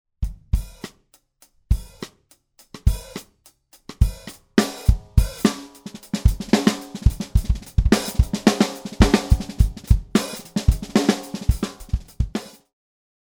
ソリッド･プライ（単板）のバーズアイ･メイプル･シェルに、レインフォースメントを搭載したスタントン･ムーア･シグネチャー･スネア。音の立ち上がりが速く、チューニングレンジの広さが特徴。